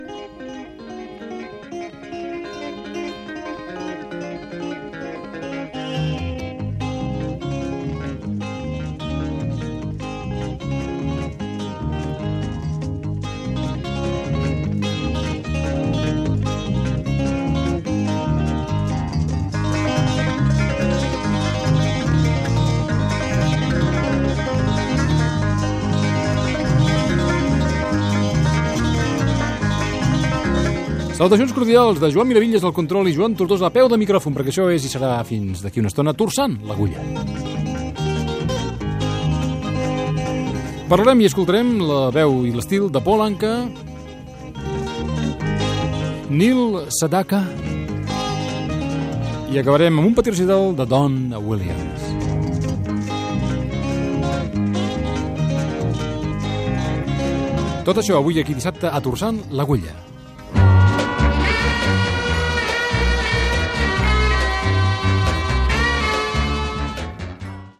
36a91ded53ccd196e20f45657a939b9225f3ddbc.mp3 Títol Ràdio 4 - Torçant l'agulla Emissora Ràdio 4 Cadena RNE Titularitat Pública estatal Nom programa Torçant l'agulla Descripció Inici del programa amb el sumari de cantants d'aquella edició. Gènere radiofònic Musical